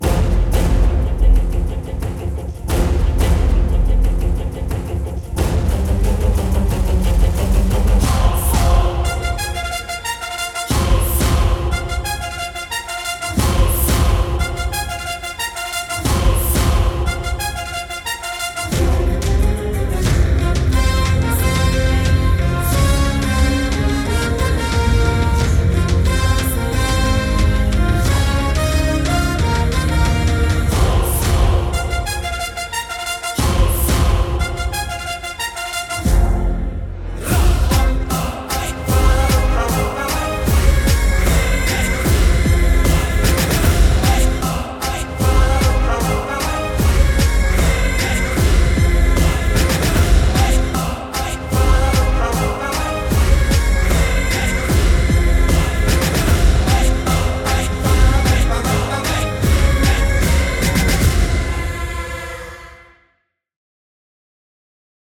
without dialogues and tiger roaring